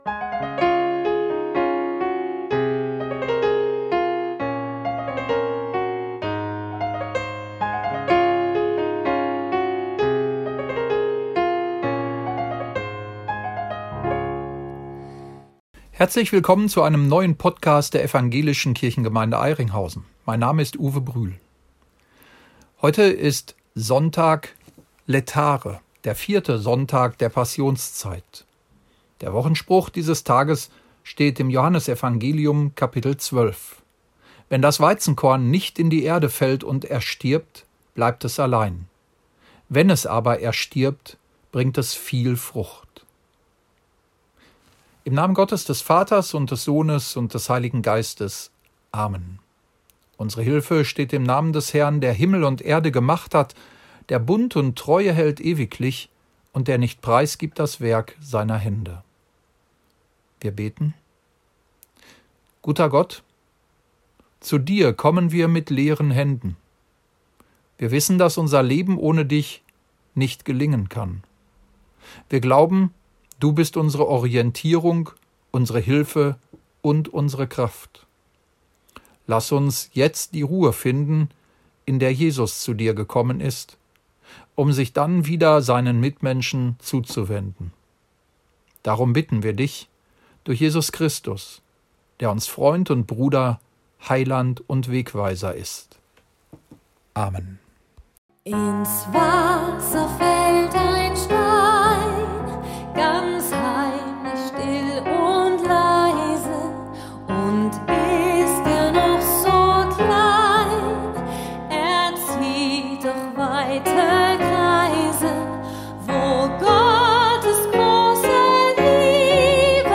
Gottesdienstpodcast